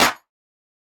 Snare 003.wav